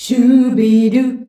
SCHUBIDU C.wav